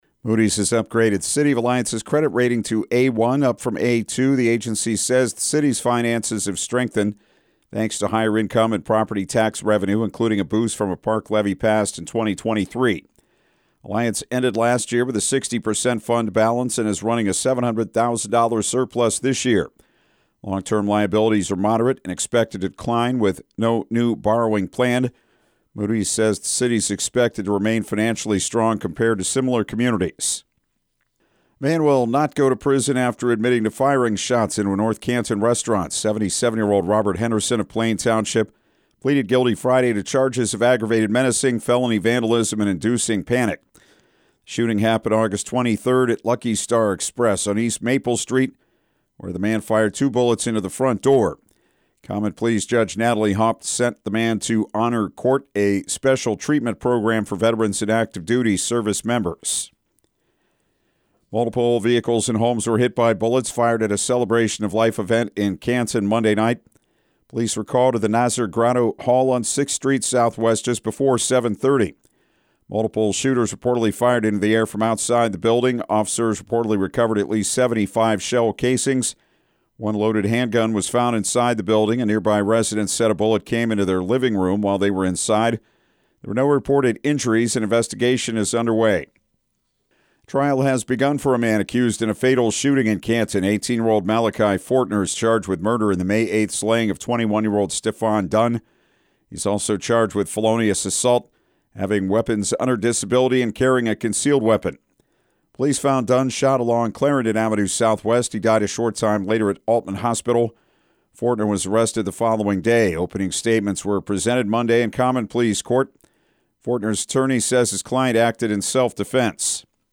6am-news-19.mp3